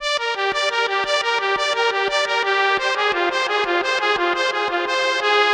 Rave Strings.wav